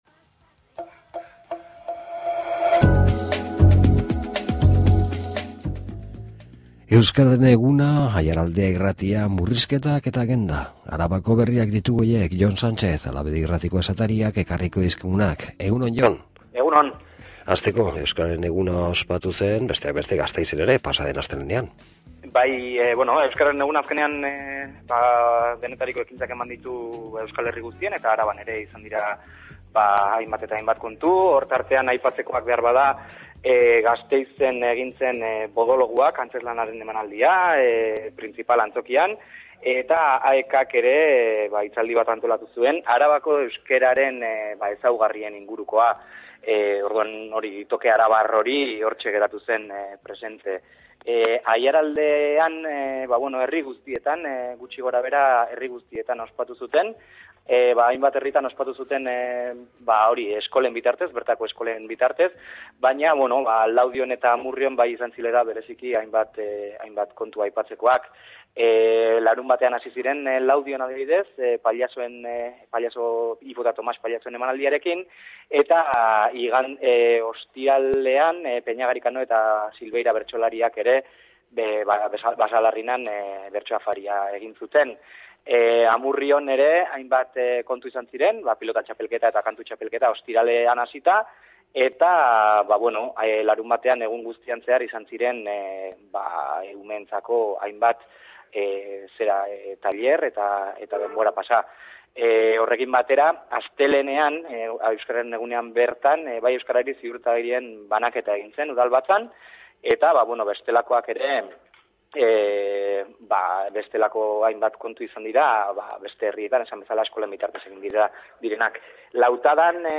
SOLASALDIA